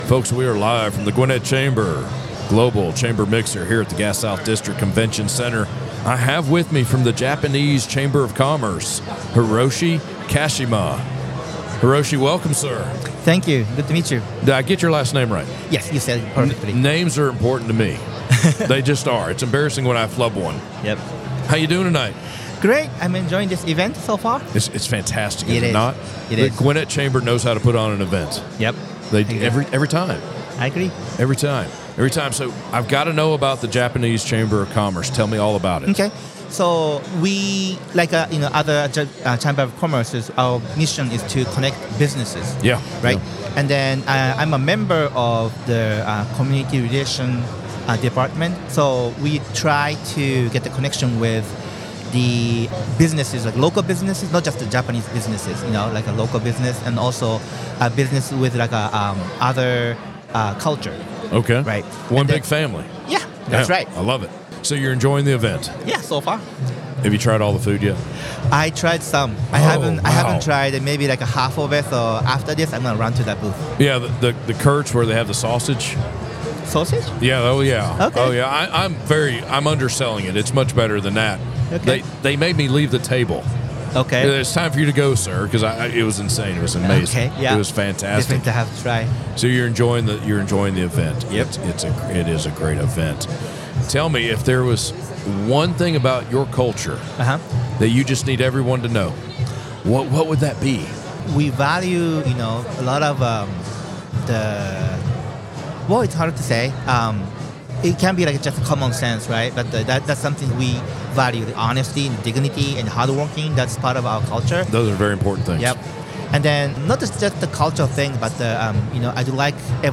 The Gwinnett Chamber of Commerce hosted it’s 2024 Global Chamber Mixer on November 7 at the Gas South Convention Center in Duluth, GA.